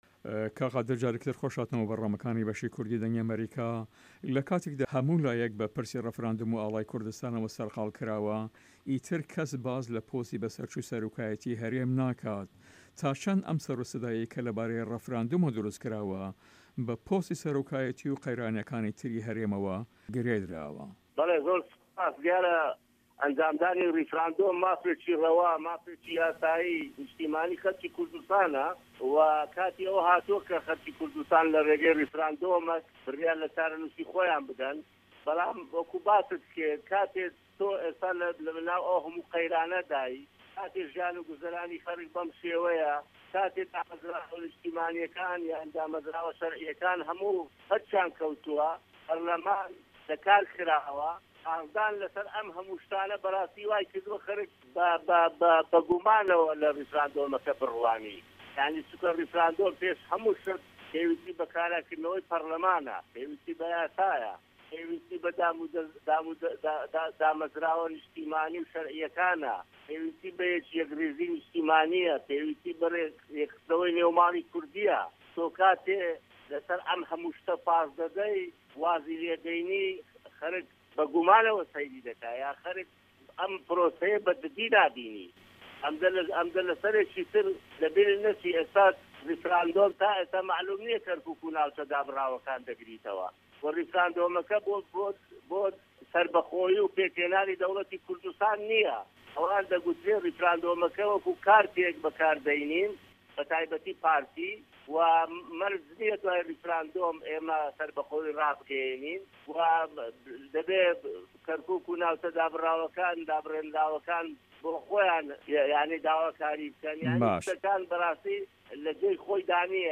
داوا لێبۆردن ده‌که‌ین و جێگاێ ئاماژه‌ پێکردنه‌ که‌ چوونیه‌تی ده‌نگی ( Audio) به‌ هۆێ هێڵی ته‌لیفۆنه‌کانه‌وه‌ زۆر باش نییه‌، که‌ چه‌ندین جار له‌ درێژایی وتو وێژه‌که‌دا هێڵه‌کان داده‌پچرا، جا هه‌وڵم دا هه‌ره‌ باشترین به‌شی وتو وێژه‌که‌ لێره‌دا بڵاو بکه‌مه‌وه‌.
Interview with Qadir Aziz